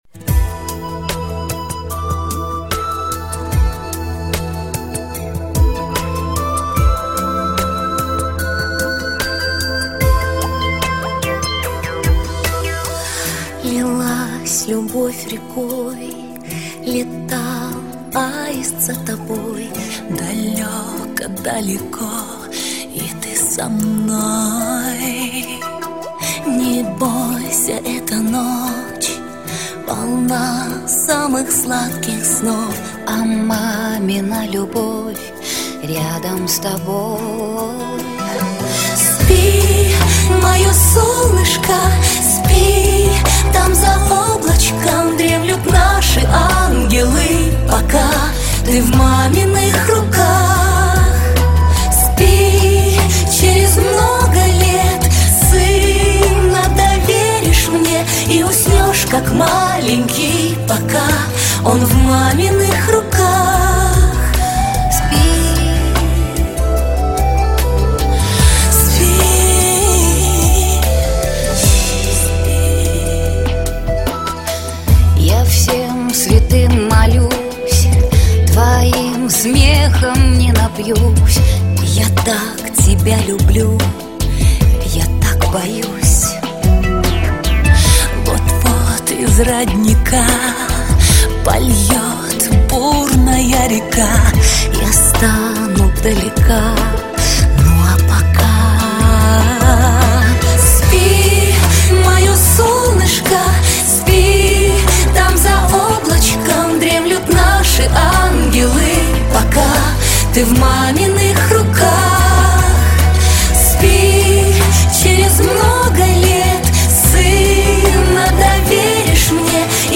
• Категория: Детские песни
Колыбельные песни
теги: колыбельная, сон